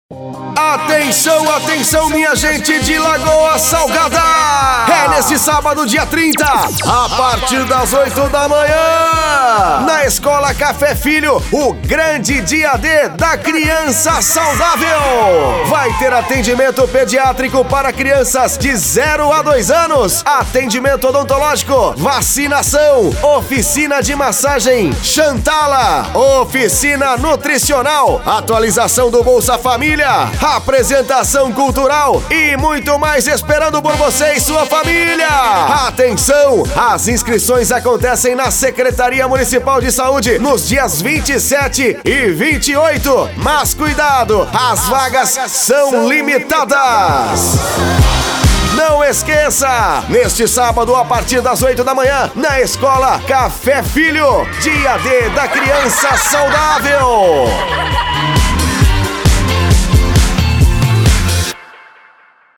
VACINAÇÃO ( VAREJO ANIMADO ):